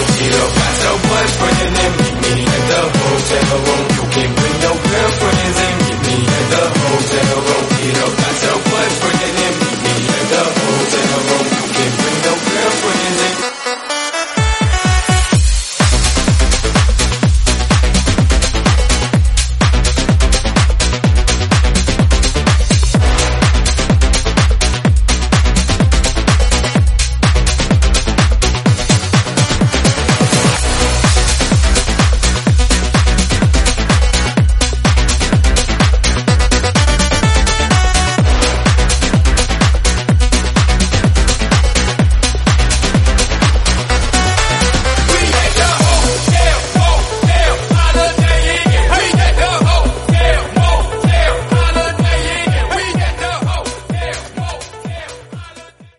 Genre: TIK TOK HITZ
Dirty BPM: 128 Time